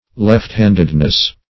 Left-handedness \Left"-hand`ed*ness\, Left-handiness